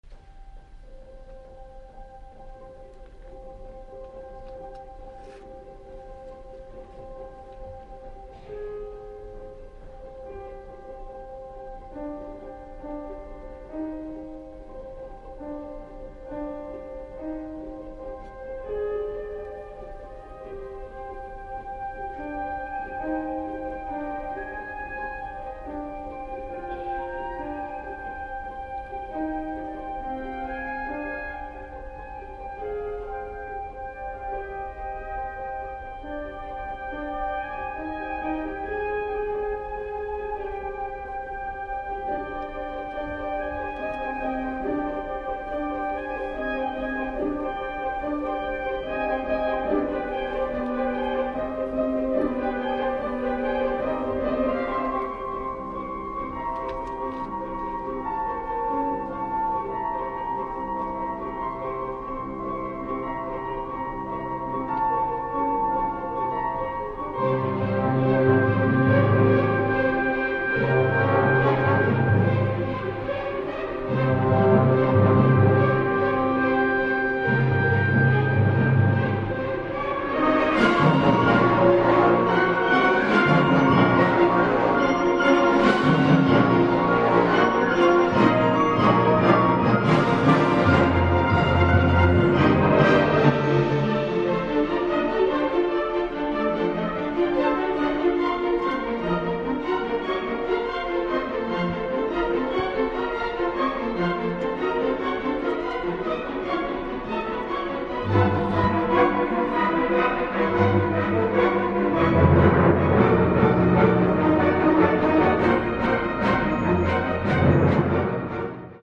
Concert Work
From Concert...
strings
small female chorus [8 voices]